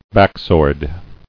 [back·sword]